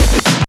NOISY STOP.wav